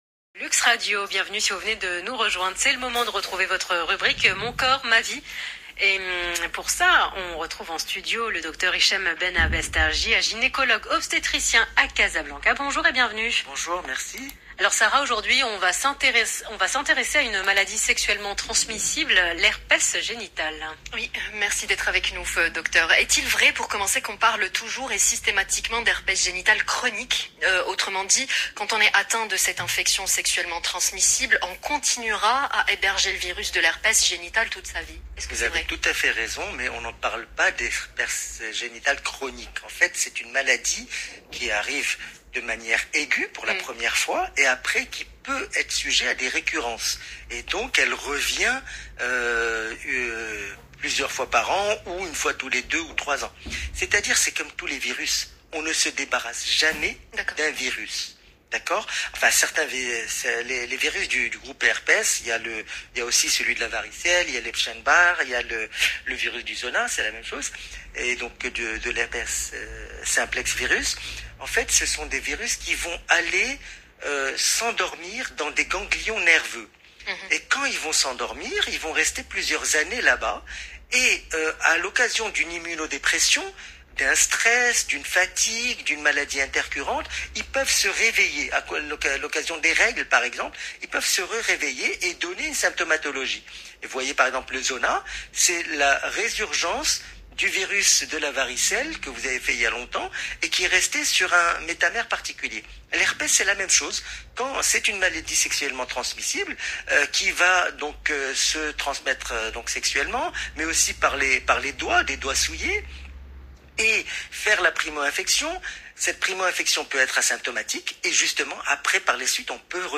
Je parle dans cette interview de l’Heure Essentielle sur Luxe Radio du 8 février 2022 de tout ce que vous voulez savoir sur l’ herpès génital : ses causes, ses symptômes, ses complications, sa gravité, ses récidives, ses traitements, son association à la grossesse..